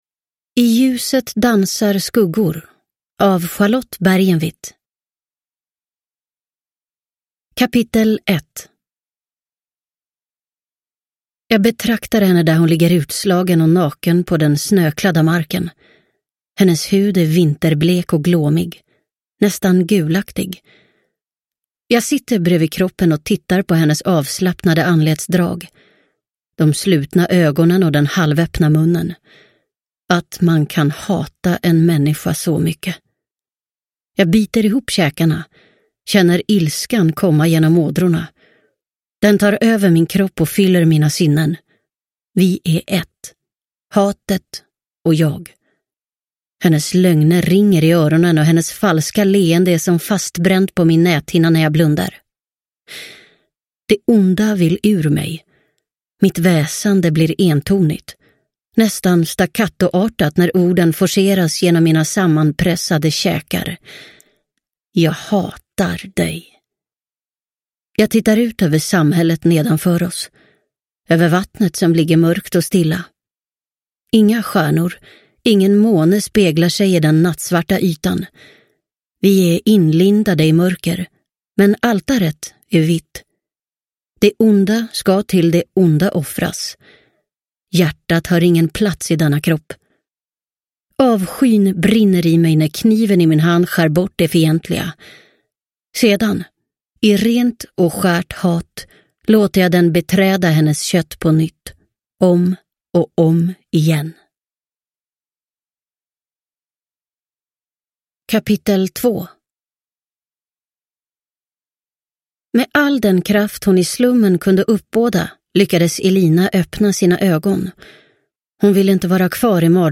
I ljuset dansar skuggor – Ljudbok – Laddas ner